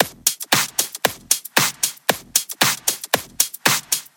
Сэмплы ударных (Electro Pop): Hard Set C
Тут вы можете прослушать онлайн и скачать бесплатно аудио запись из категории «Electro Pop».